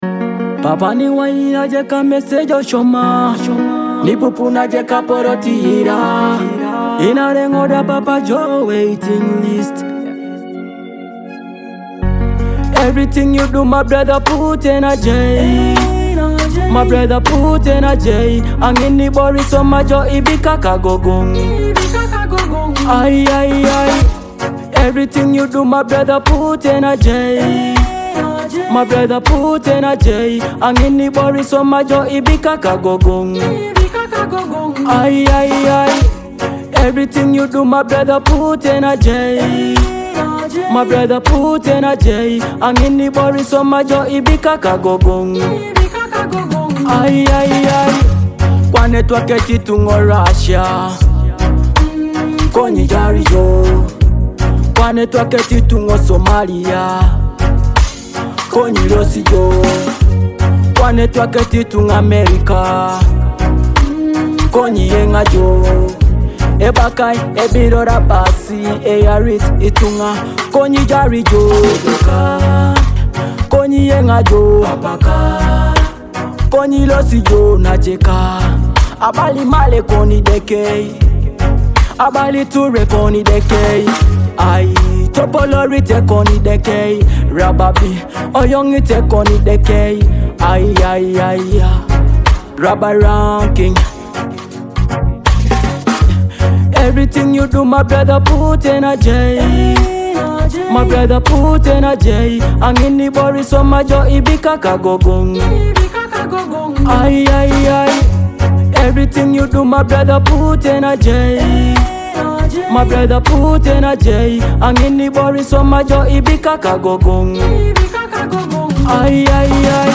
an uplifting gospel-infused track with melodic beats